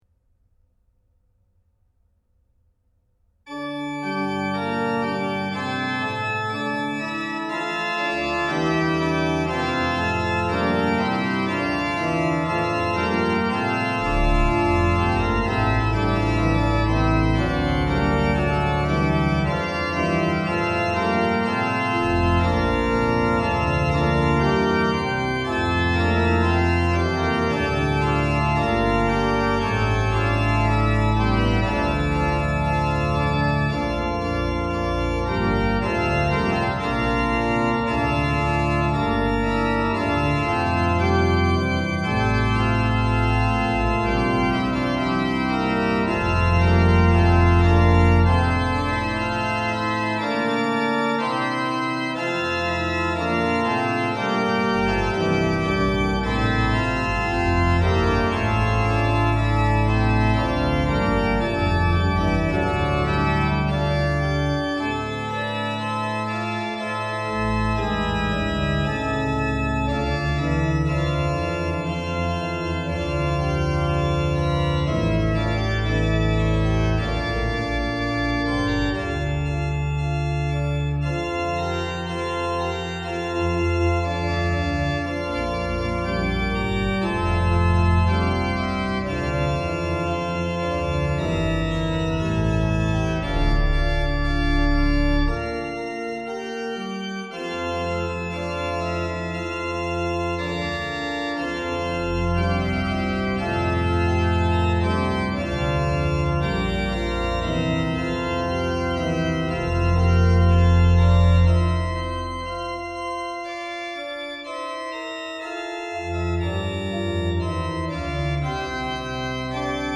The organ is built in the style of the North German Baroque, on the model of the smaller Schnitger-type instruments which may be found for example in Grasberg, Pellworm, Noordbroek or Uithuizen.
However, adjusting the relative volume of the channels (Moist + Wet + Surround) is possible by loading the additional configuration Menesterol – Surround – 3Ch.